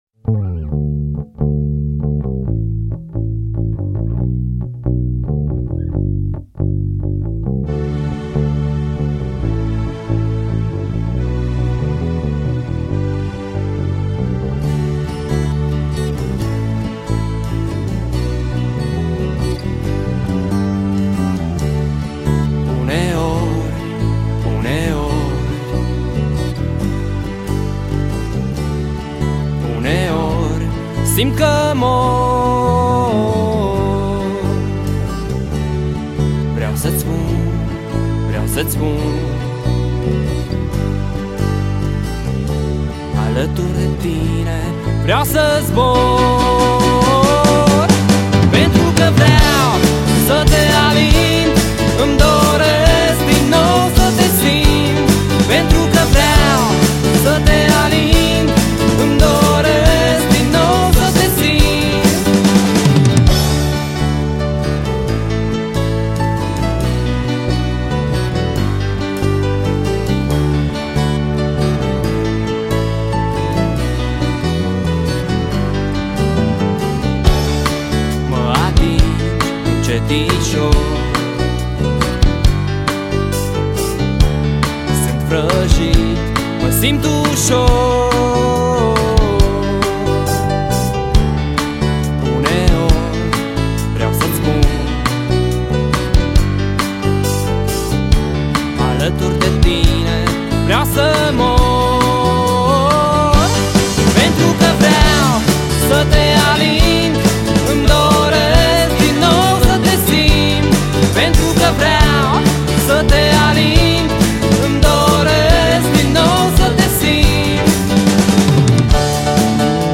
rock alternativ
voce, chitară
claviaturi
chitara bas
baterie